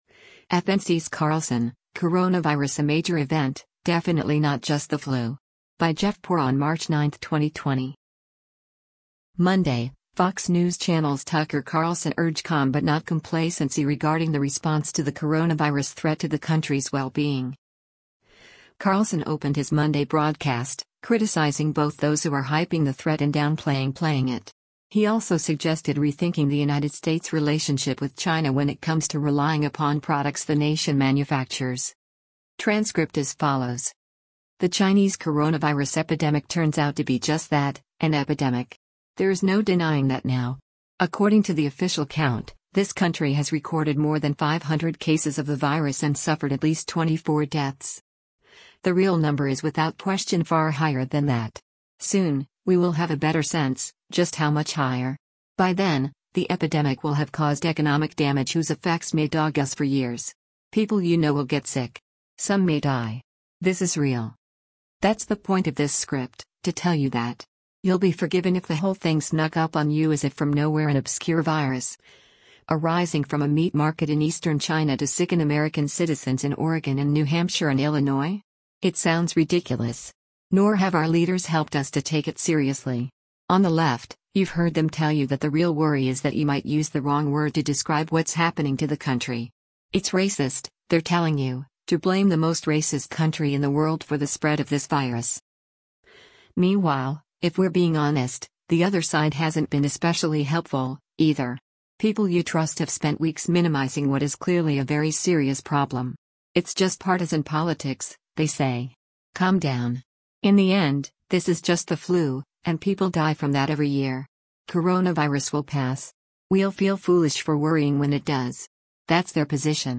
Carlson opened his Monday broadcast, criticizing both those who are hyping the threat and downplaying playing it.